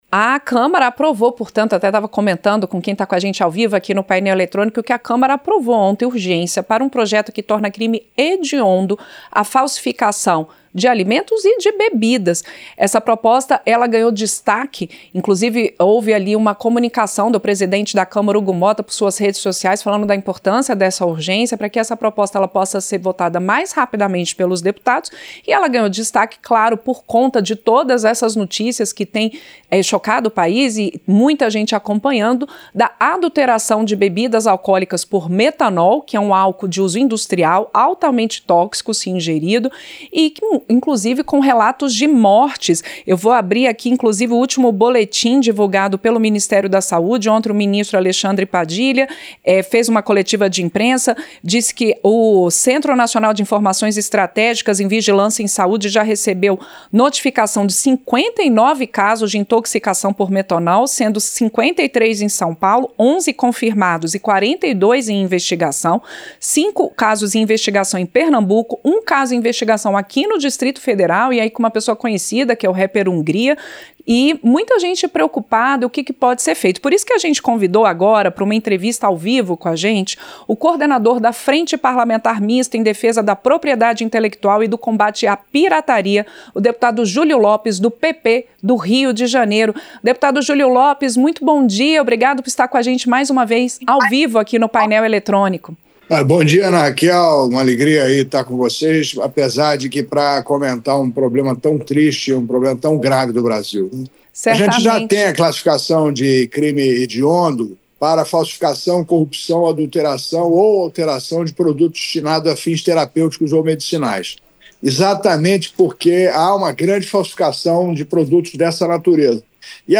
Entrevista - Dep. Julio Lopes (PP-RJ)